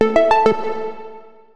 効果音のフリー素材です。
効果音2